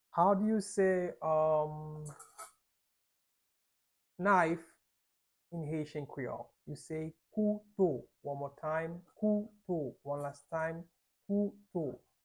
How to say "Knife" in Haitian Creole - "Kouto" pronunciation by a native Haitian teacher
“Kouto” Pronunciation in Haitian Creole by a native Haitian can be heard in the audio here or in the video below:
How-to-say-Knife-in-Haitian-Creole-Kouto-pronunciation-by-a-native-Haitian-teacher.mp3